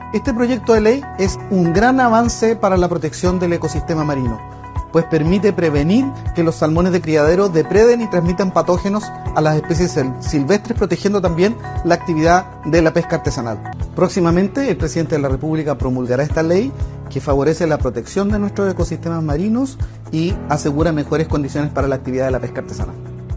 Asimismo, establece la obligación de recaptura con la posibilidad de incluir en esta tarea a la flota pesquera artesanal, junto con establecer el concepto de “captura accidental” para aquellos salmones atrapados después del periodo en que las empresas deben recapturar las especies fugadas. Al respecto, el Senador por la región de Los Lagos, Iván Moreira, explicó los alcances de la iniciativa.